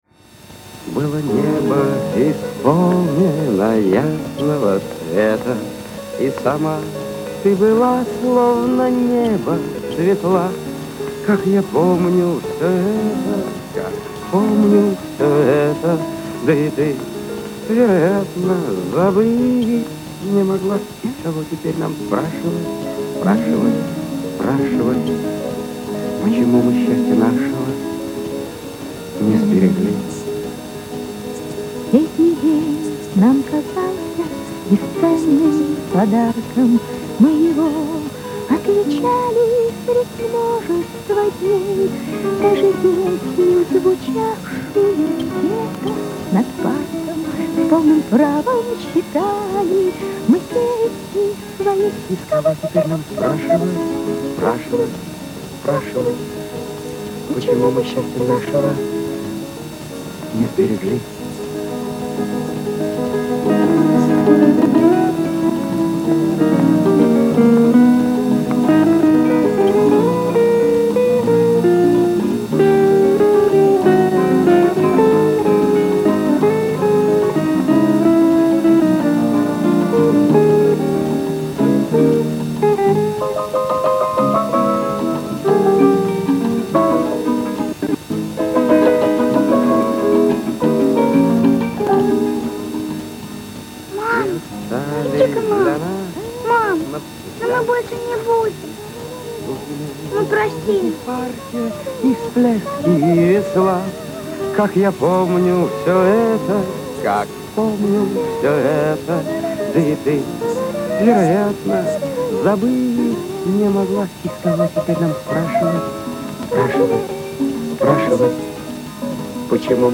Музыка к кинофильмам
Режим: Mono